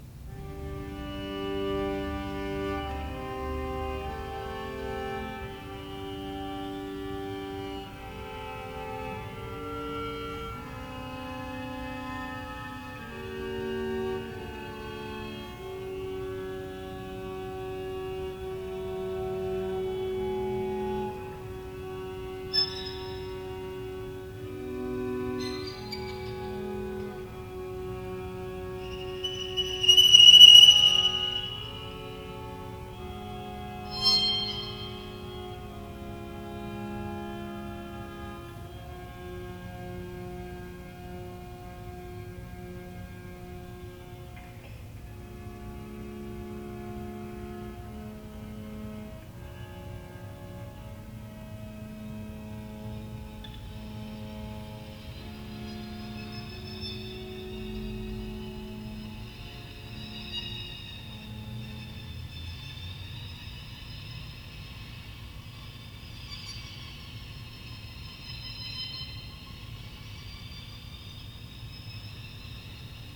electric string quartet